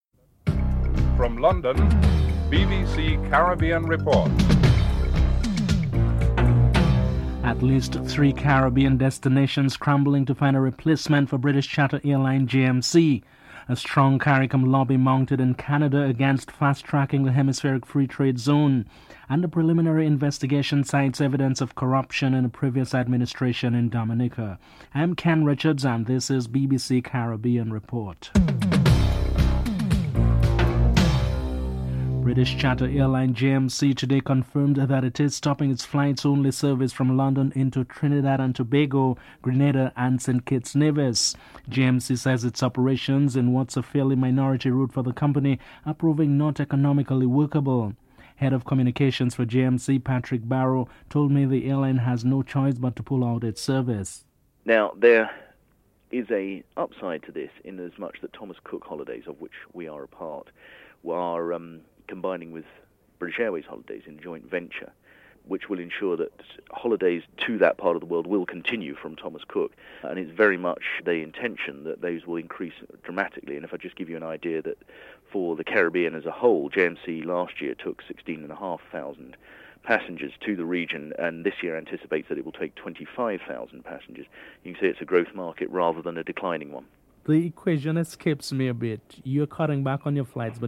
1. Headlines (00:00-00:30)
3. Strong Caricom lobby is mounted in Canada against fast tracking the hemispheric free trade zone. Prime Minister Denzil Douglas is interviewed (05:46-08:52)